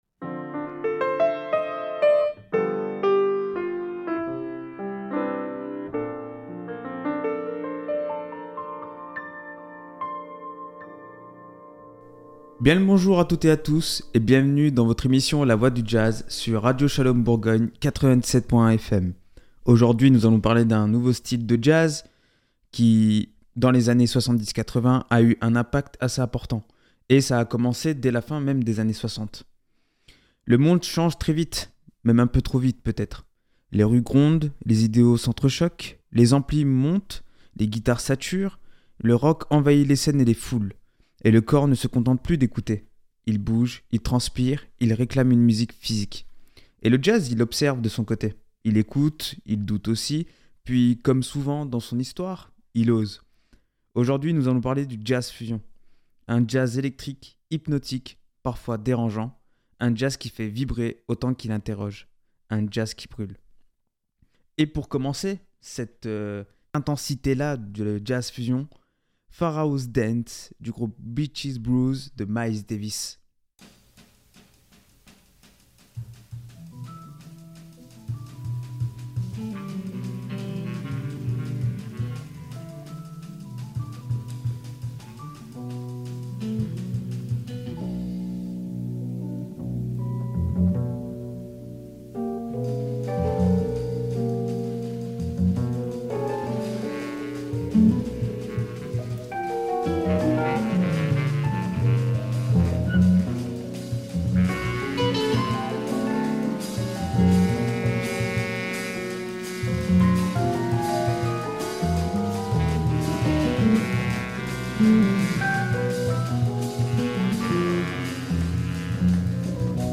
Dans cet épisode de La Voie du Jazz, nous plongeons au cœur du Jazz Fusion, cette musique qui créer des sons uniques , libère les formes et fusionne des styles de jazz de manière unique .